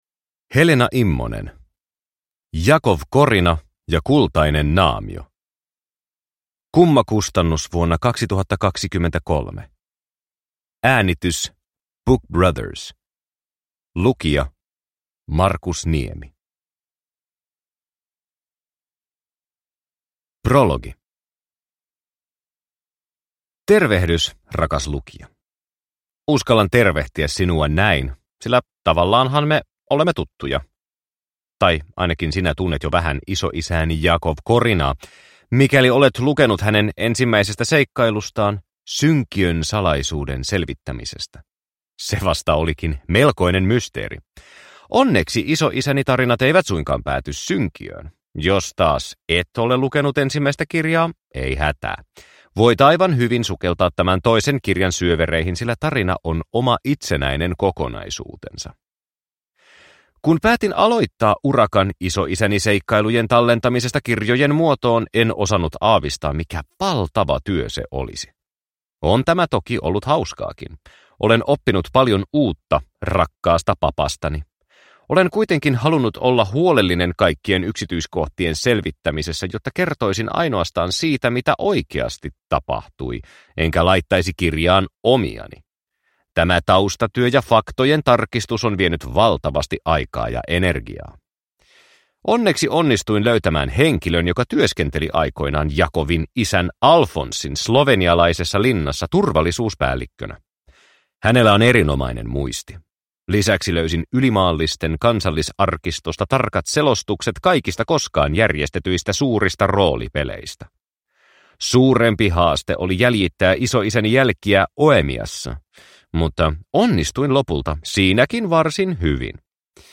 Jakov Korina ja kultainen naamio – Ljudbok – Laddas ner